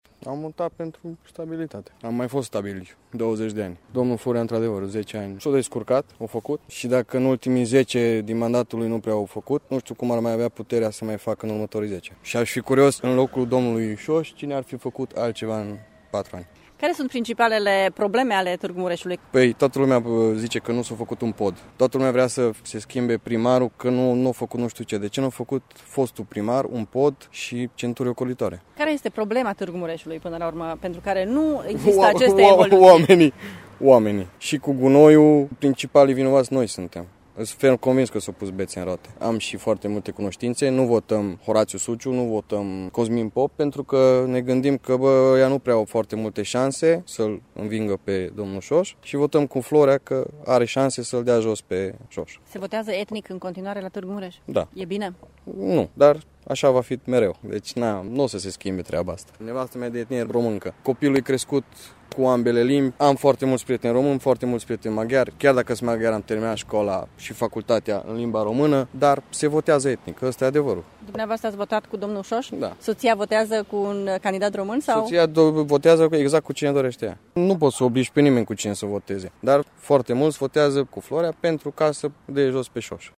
Așa crede un târgumureșean de etnie maghiară întâlnit azi de reporterul Radio Tg.Mureș. Acesta spune că a votat pentru continuitate și stabilitate la Primăria municipiului: